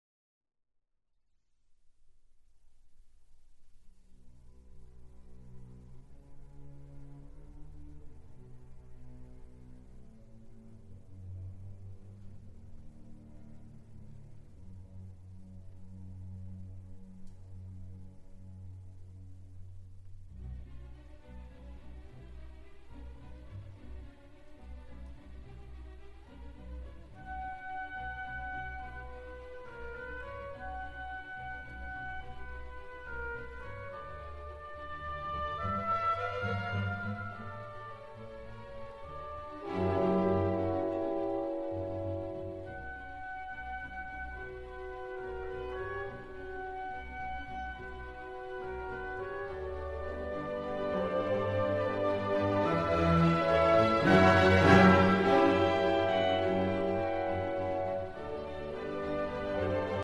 Allegro moderato - Great Classical Music